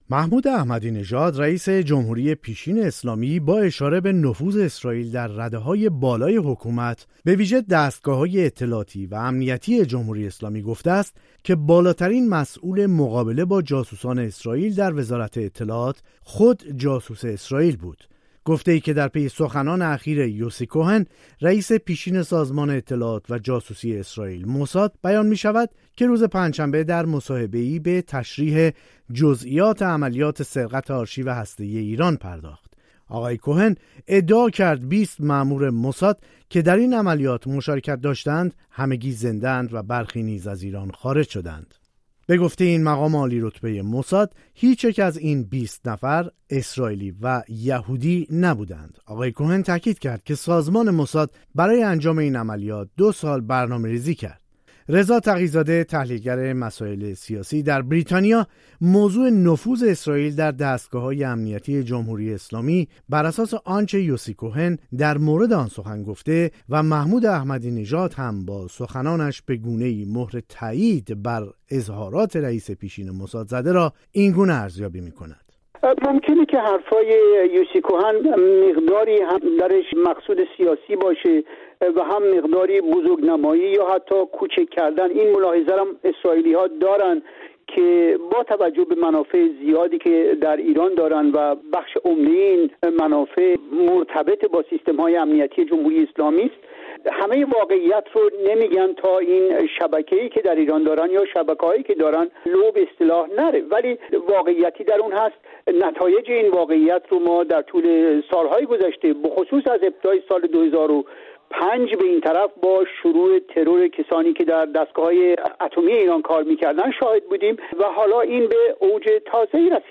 گفت و گو با یک تحلیلگر